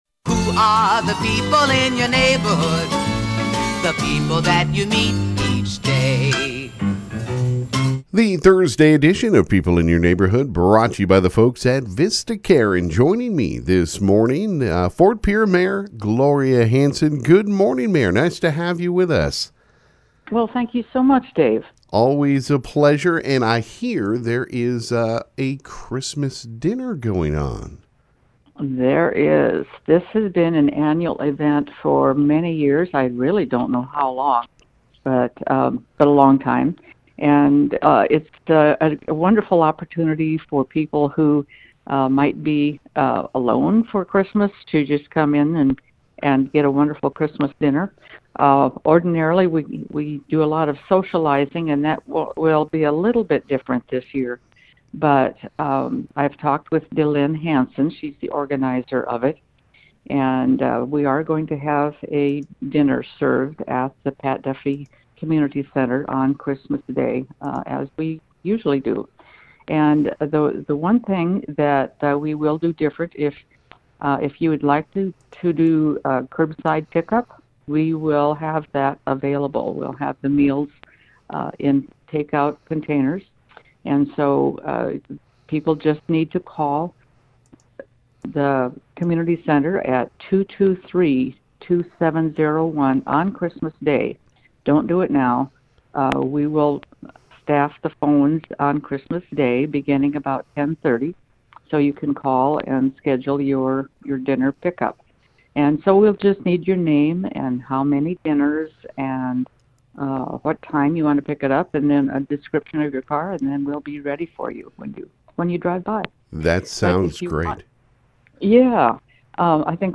Ft. Pierre Mayor Gloria Hanson called in and talked about the upcoming Annual Community Christmas Dinner at the Pat Duffy Community Center. She talked about the meal and how you can enjoy it on Christmas Day this year.